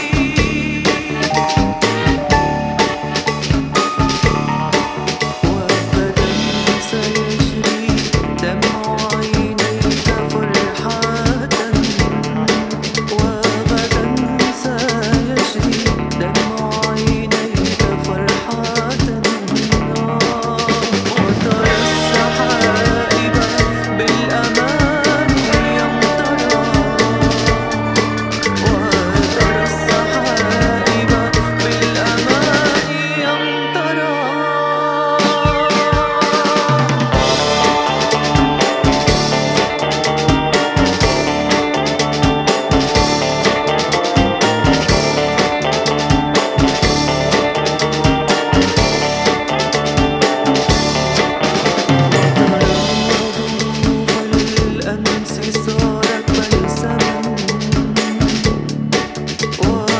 a group known for their Ethio-jazz fusion.